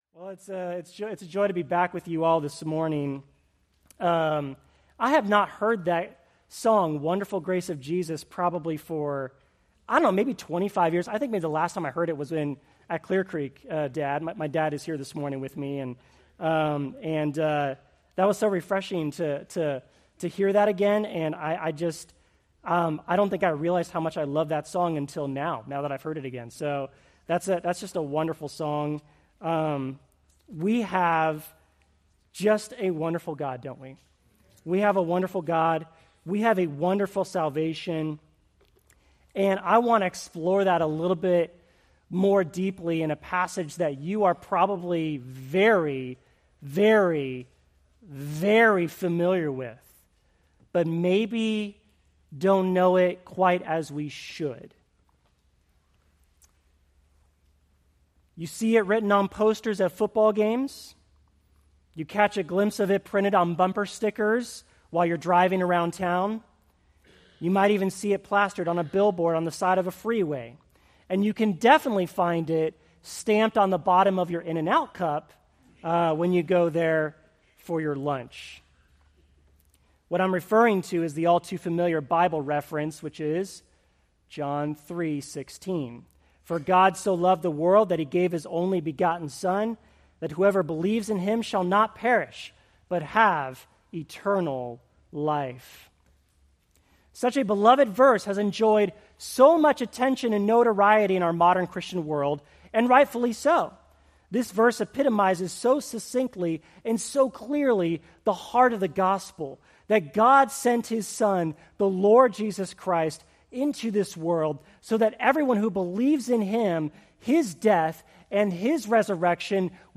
Preached July 21, 2024 from Jeremiah 29:11